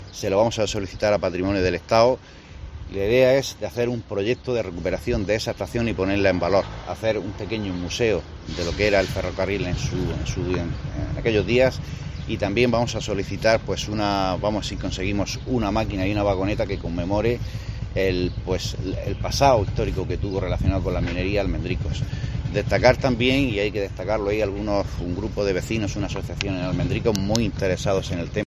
Ángel Meca, concejal PP del Ayto Lorca